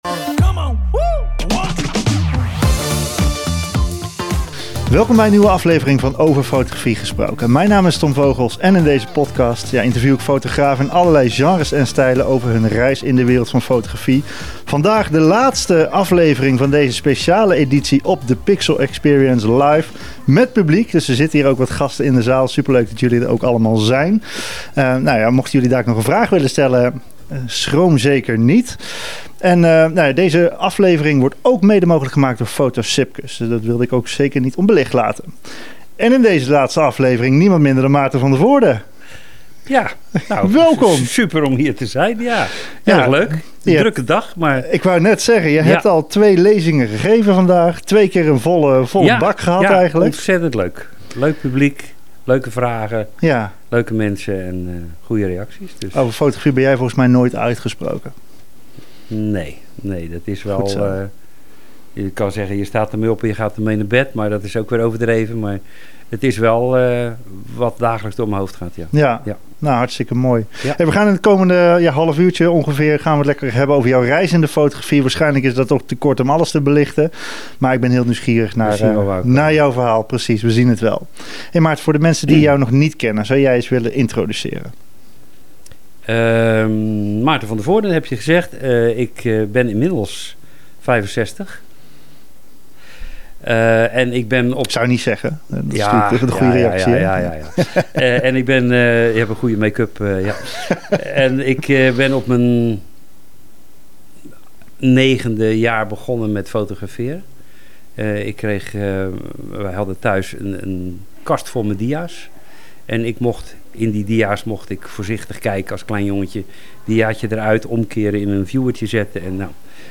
In deze zomerspecial vind je het gesprek terug wat ik voerde op fotografie event The PixelExperience